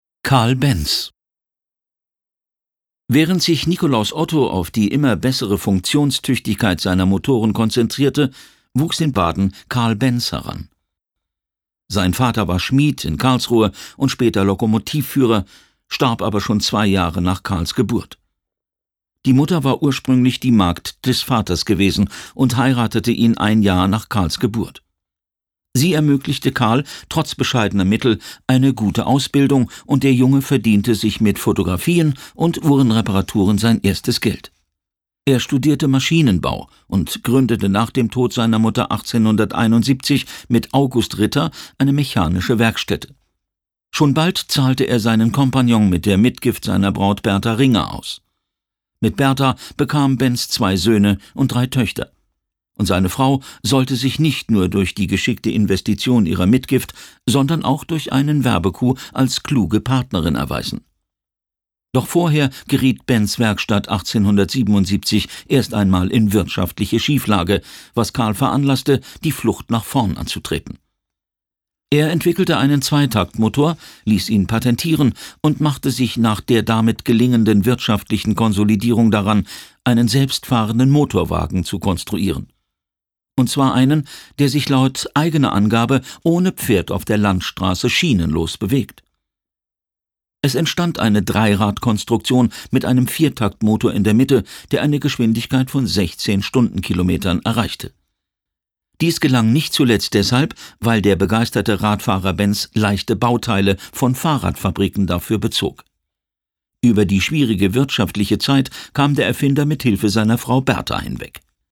Schlagworte Erfinder • Erfindungen • Hörbuch • Mobilität • Sachthemen